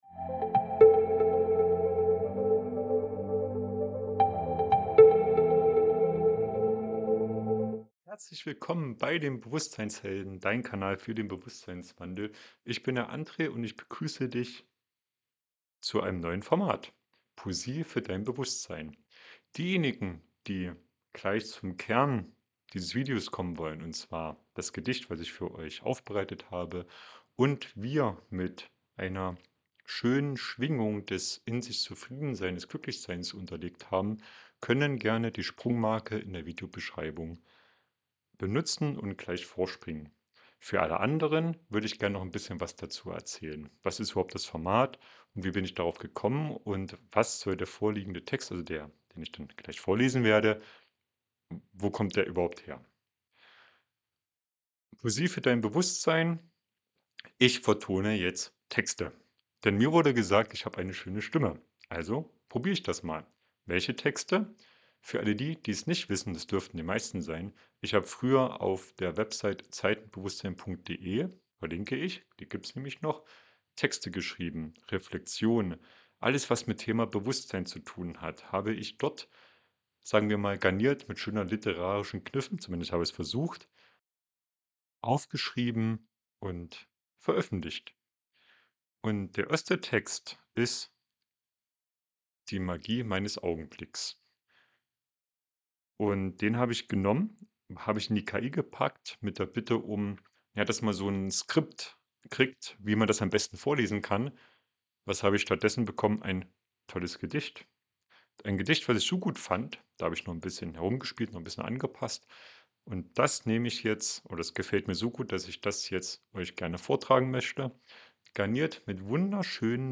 Dieses Video ist eine einzigartige Kombination aus Poesie und